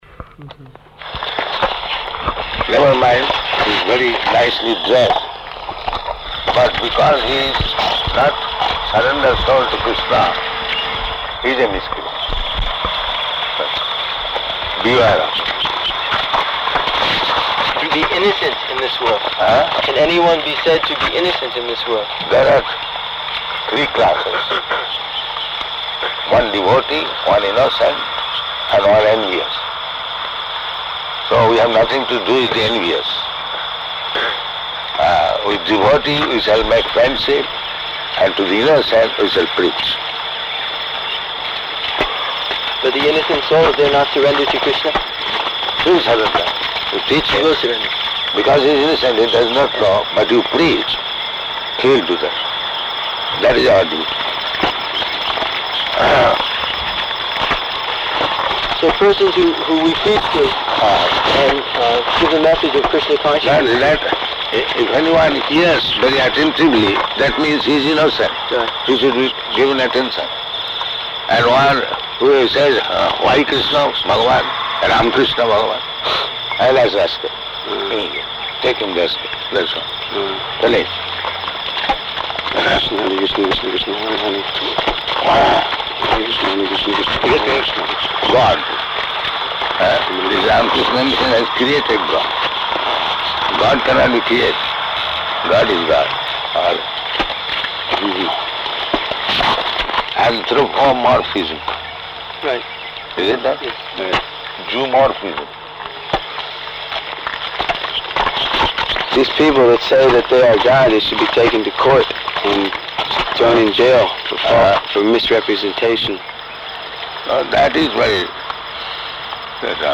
Morning Walk --:-- --:-- Type: Walk Dated: May 3rd 1976 Location: Fiji Audio file: 760503MW.FIJ.mp3 Prabhupāda: Never mind he's very nicely dressed.